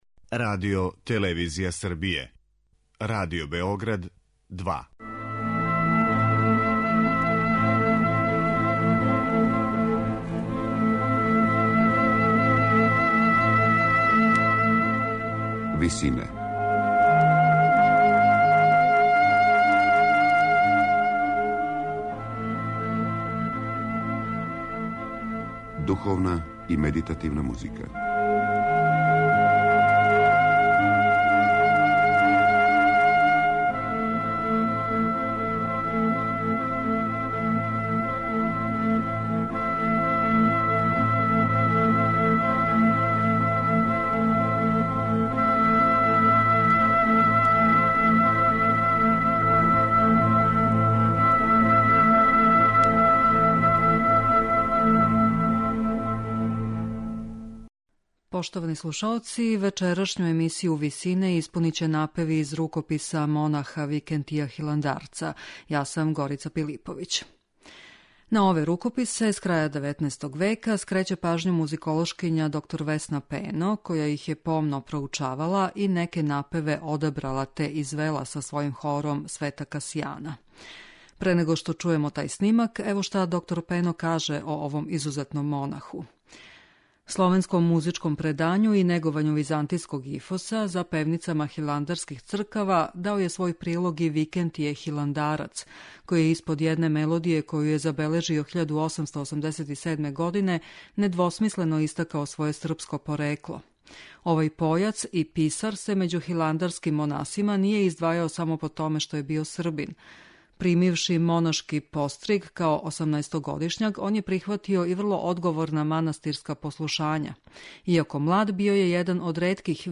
Емисија духовне музике
На крају програма, у ВИСИНАМА представљамо медитативне и духовне композиције аутора свих конфесија и епоха.